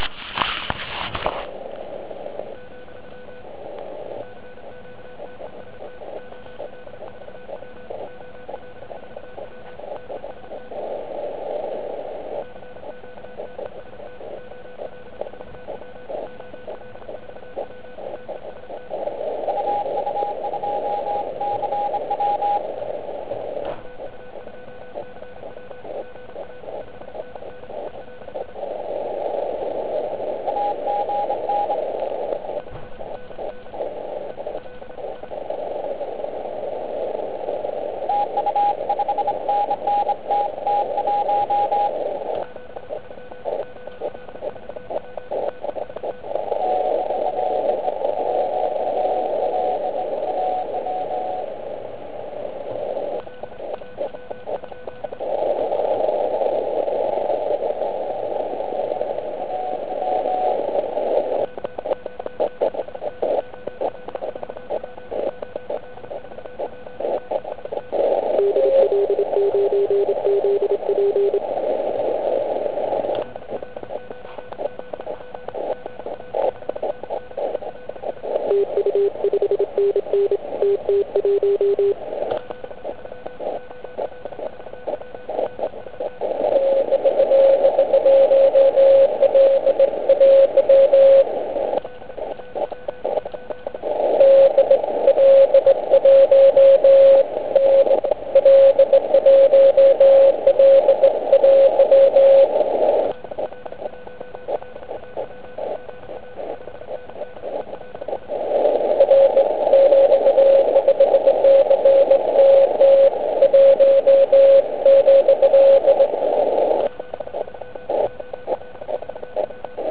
Je začátek závodu a já ho samozřejmě rozjíždím na 40m